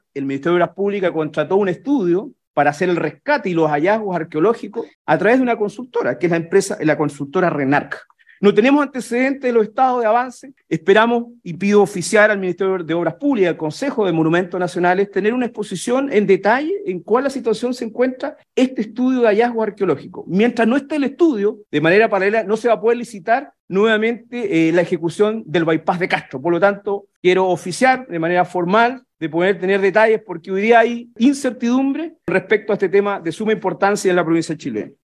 En conversación con Radio Sago, el consejero chilote destacó que, a pesar de que la obra tiene un avance del 60 por ciento, actualmente no hay una señal clara ni certeza sobre cuándo se reanudarán los trabajos en esta ruta alternativa, que es de vital importancia para la conectividad en la isla Grande de Chiloé.